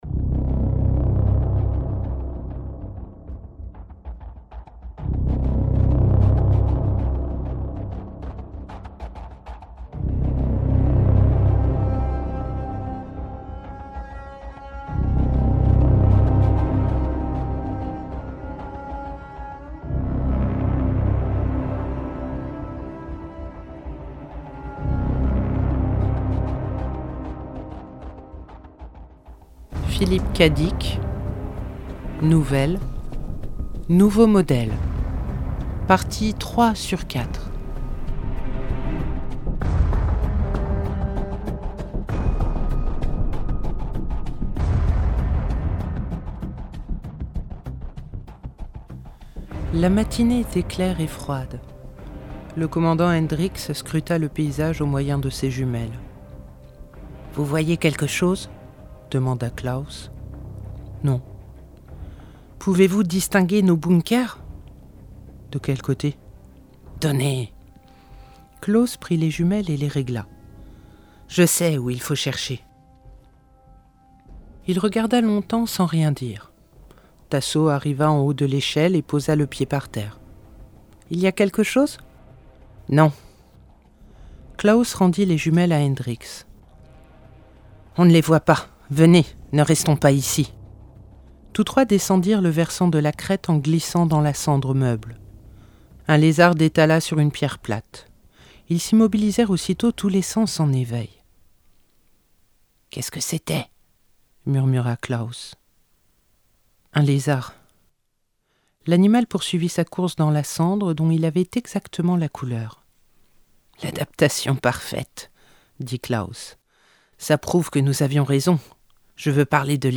🎧 Nouveau Modèle – Philip K. Dick - Radiobook